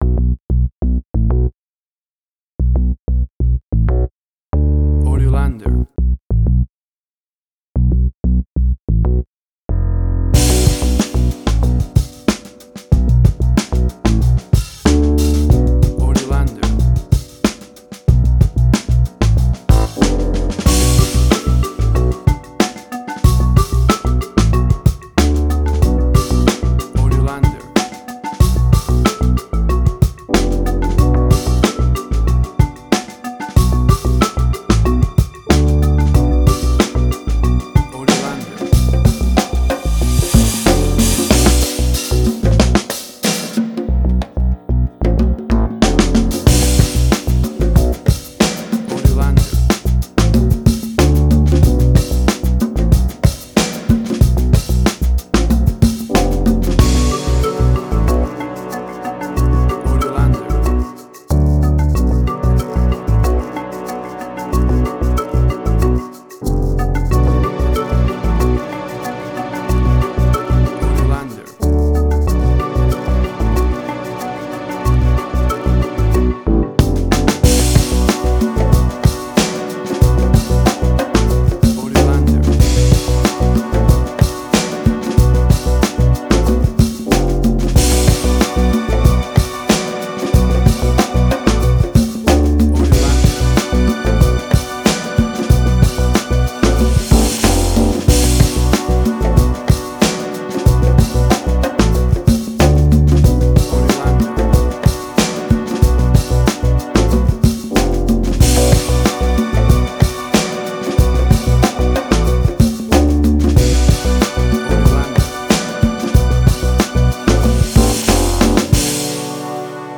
Suspense, Drama, Quirky, Emotional.
WAV Sample Rate: 16-Bit stereo, 44.1 kHz
Tempo (BPM): 93